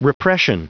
Prononciation du mot repression en anglais (fichier audio)
Prononciation du mot : repression